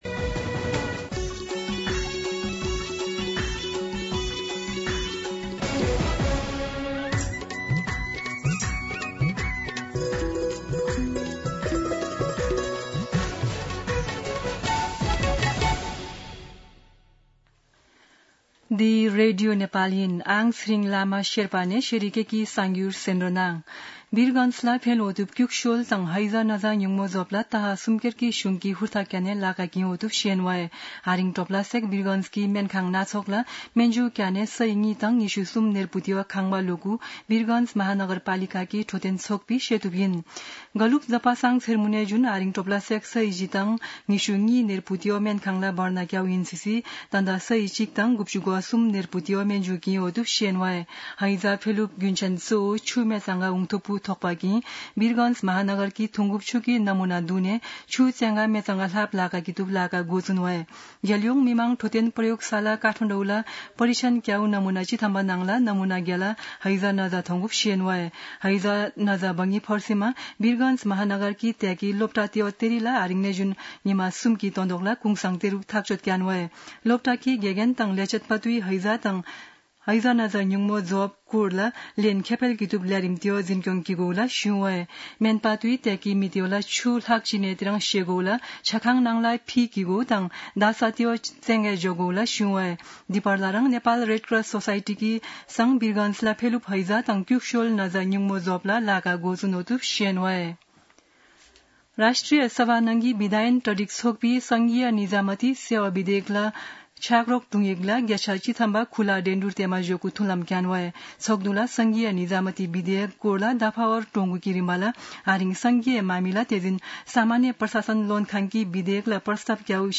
शेर्पा भाषाको समाचार : ११ भदौ , २०८२
Sherpa-News-05-11.mp3